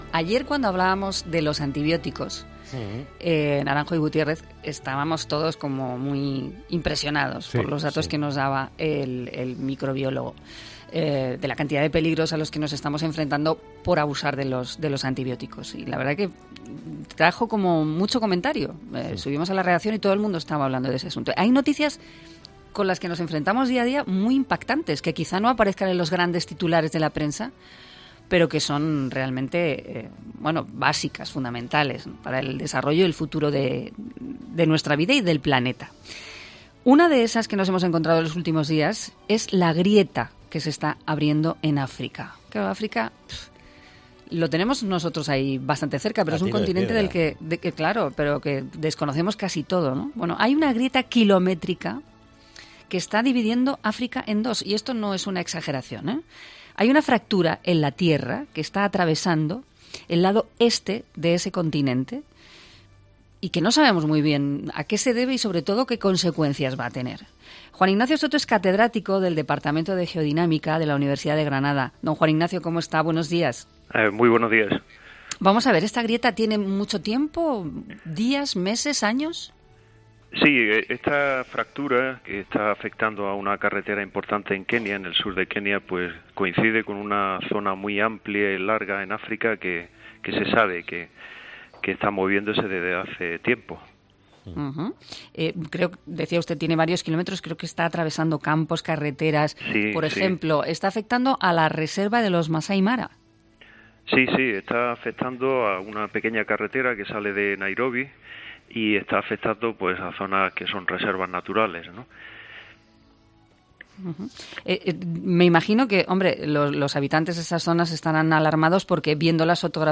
ha sido entrevistado este jueves en ‘Herrera en Cope’